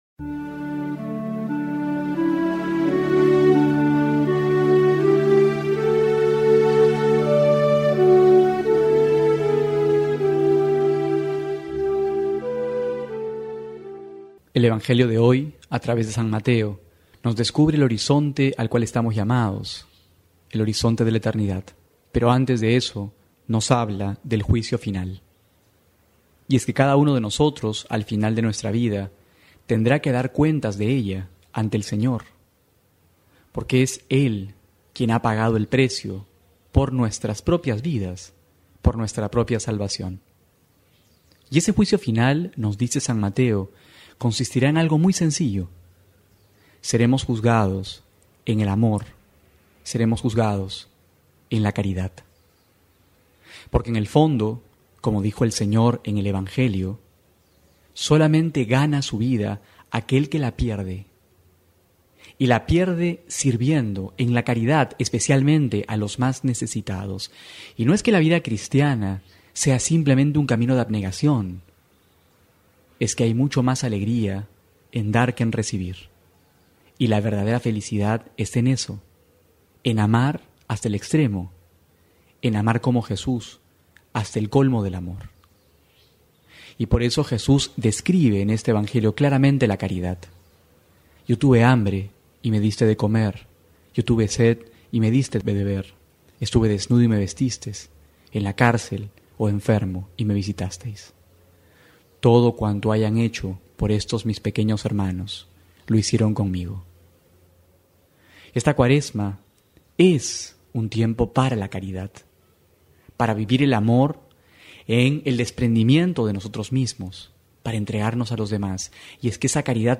Homilía para hoy: Mateo 25,31-46
febrero27-12homilia.mp3